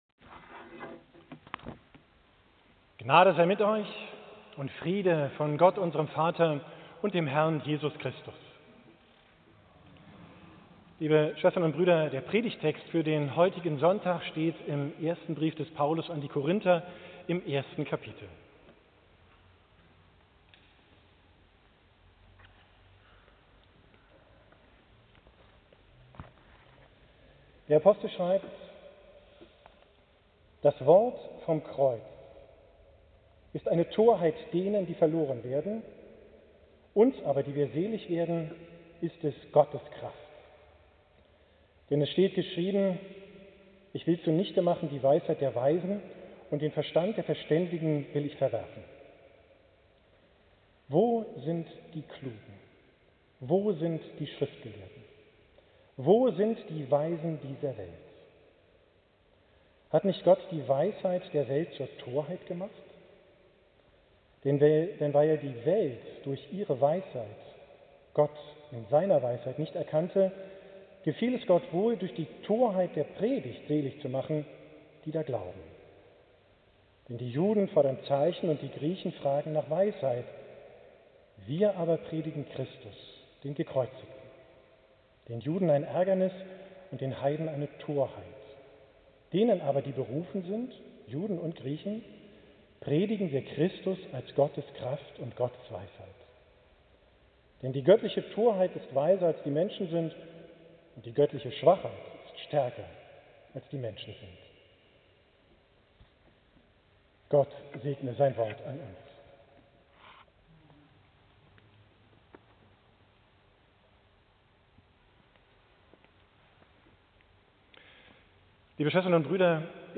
Predigt vom 5. Sonntag nach Trinitatis, 4. VII 2021